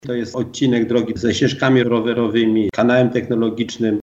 Dla przypomnienia w 2021 była realizowana przebudowa blisko półtora kilometra drogi 1051r w Kotowej Woli i Zbydniowie. Mówił o tym starosta stalowowolski Janusz Zarzeczny